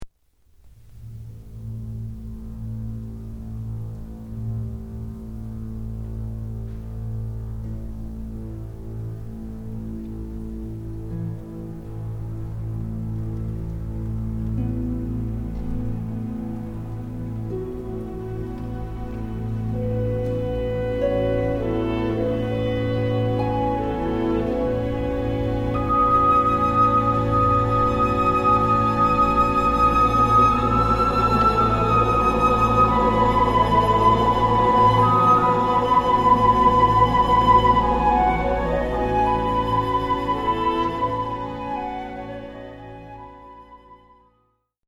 At the very opening of this symphonie choreographique, as Ravel himself called it, a series of superimposed fifths from lower to upper strings, con sordino, provides a background similar to that at the opening of “Sirènes,” or Schmitt’s “Les enchantements sur la mer” from La Tragédie de Salomé, op. 50 (1907). At measure 6, the entrance of stopped horns seems reminiscent of these earlier pieces as well.
And yet it is the entrance of the offstage chorus, echoing the horn motive, that takes the idea of Debussy’s influence beyond mere circumstantial resemblance.